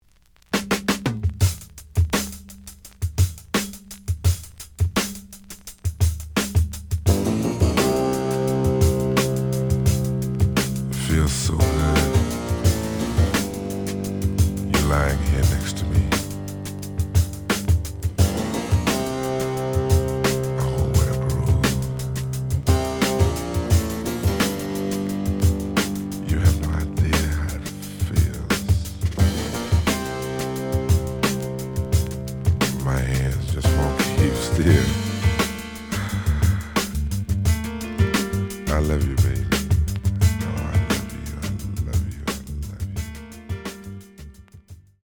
The audio sample is recorded from the actual item.
●Genre: Soul, 70's Soul
Some noise on beginning of A side.